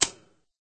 clunk_1.ogg